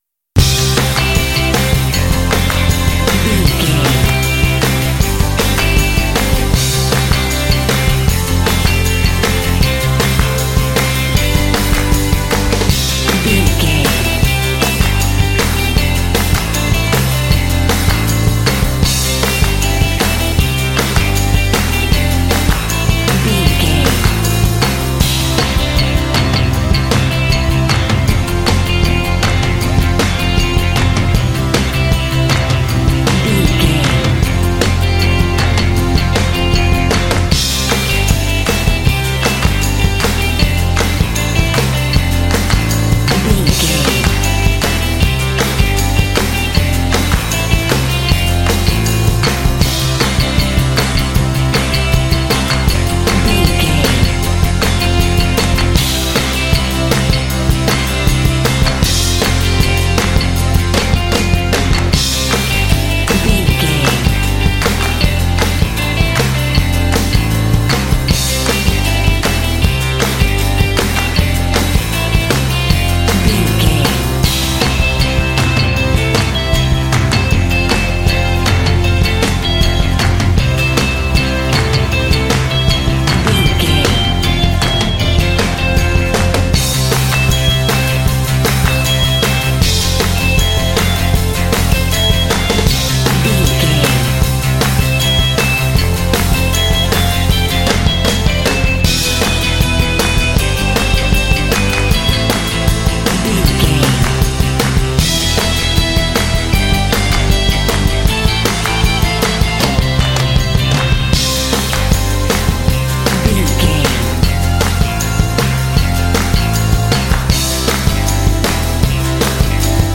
This lively rock track is ideal for action and sports games.
Epic / Action
Uplifting
Ionian/Major
Fast
driving
bouncy
energetic
bass guitar
electric guitar
synthesiser
classic rock